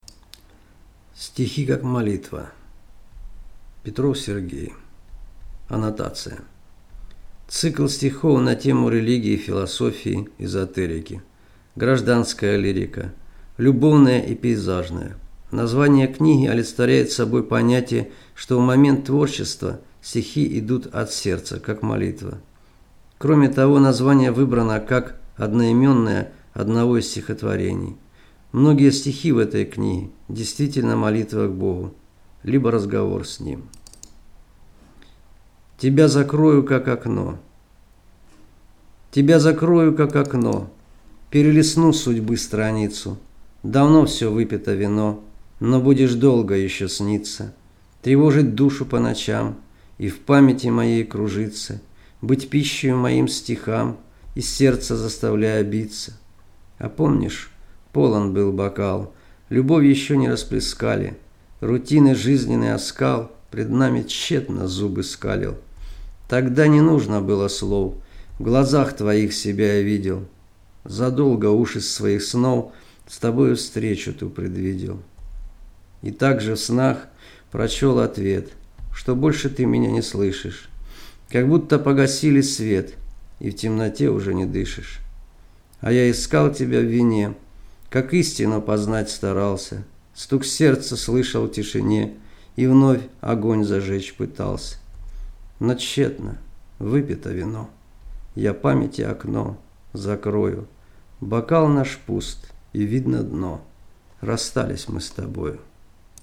Аудиокнига Стихи как молитва | Библиотека аудиокниг